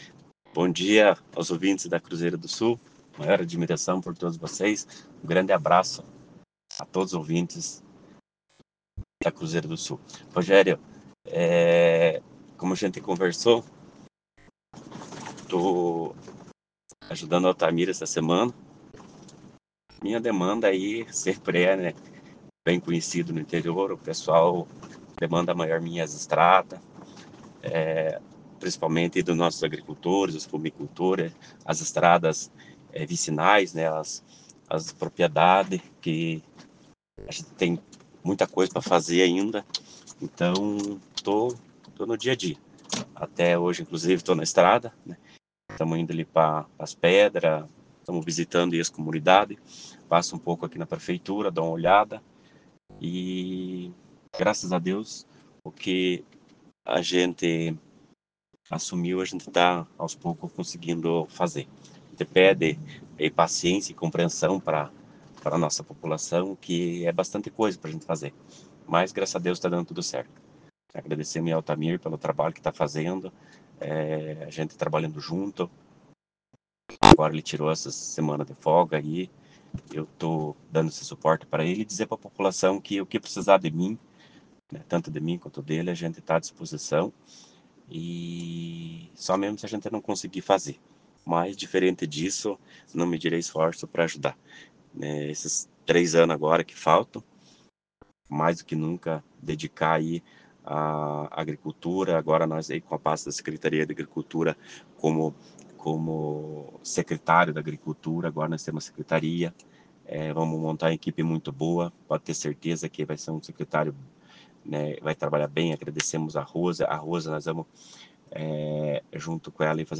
CURZEIRO ENTREVISTA JOSÉ AÍLTON VASCO, PREFEITO INTERINO DE PALMEIRA ATÉ SEXTA-FEIRA (19) | Rádio Cruzeiro
Conversamos com o vice-prefeito, no momento prefeito interino, que falou sobre a sua expectativa para esses cinco dias à frente do Executivo.
ZE-AILTON-PREFEITO-INTERINO.mp3